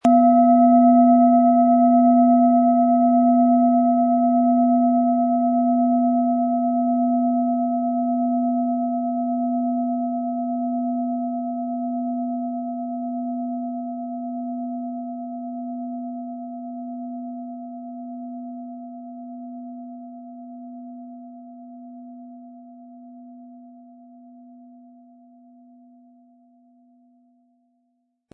Planetenschale® Gefühle erkennen und leben & Spirituelle Führung wahrnehmen mit Mond & Platonisches Jahr, Ø 15,1 cm inkl. Klöppel
• Mittlerer Ton: Platonisches Jahr
PlanetentöneMond & Platonisches Jahr
MaterialBronze